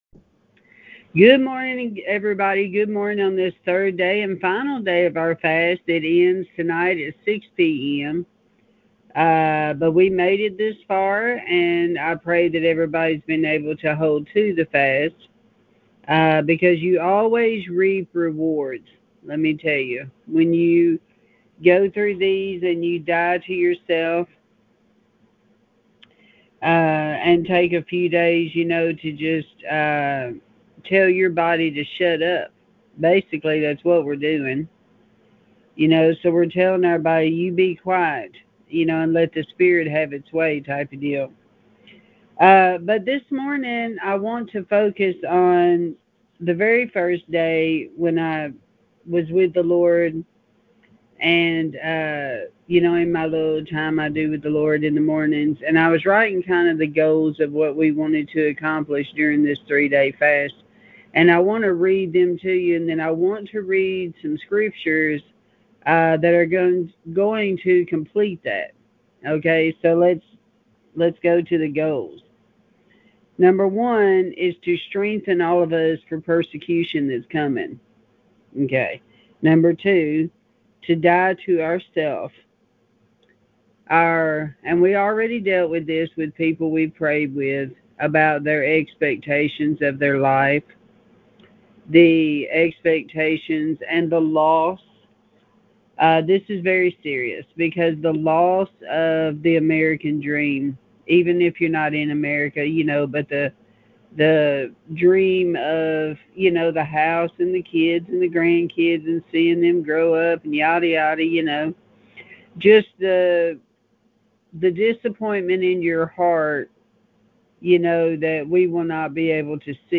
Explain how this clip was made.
This is the recording from the call recapping what God did this week and final prayers.